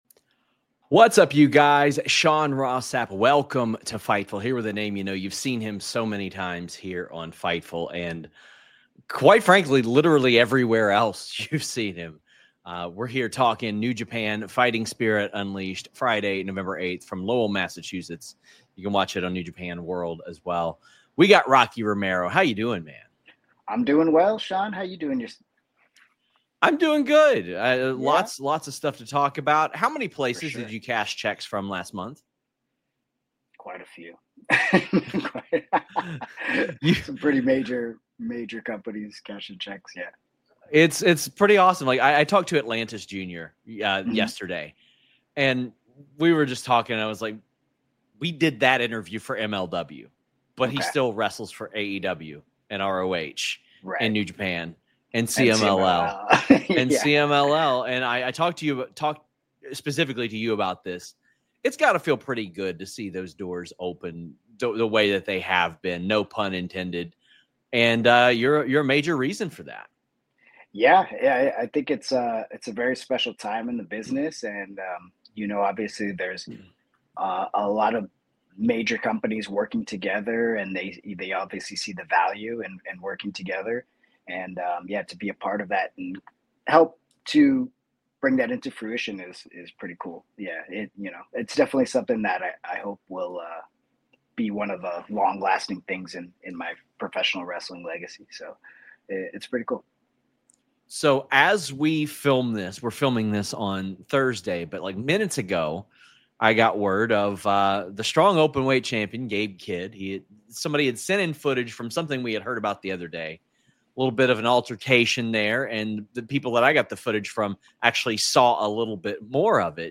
Rocky Romero: Kenny Omega's Return Being In NJPW, John Cena, NJPW-AEW, Wrestle Dynasty | Interview | Fightful News